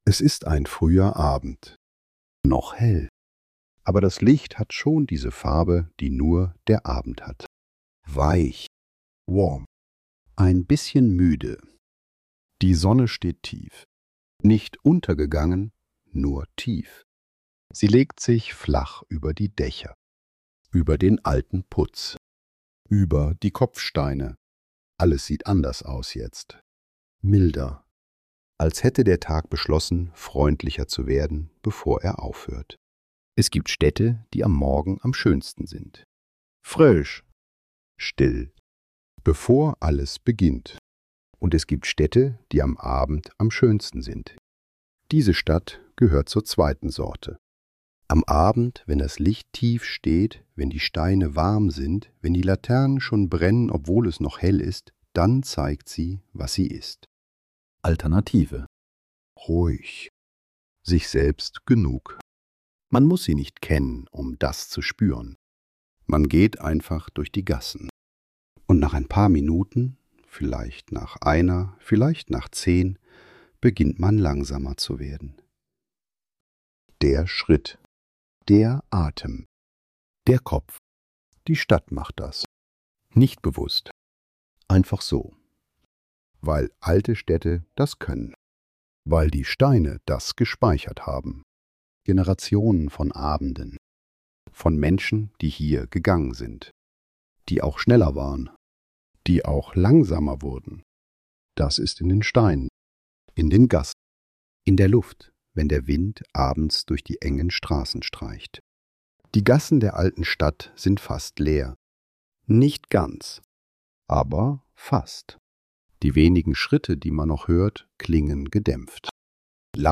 Die Gassen im Abendlicht ~ Stille Orte — Schlafgeschichten für die Nacht Podcast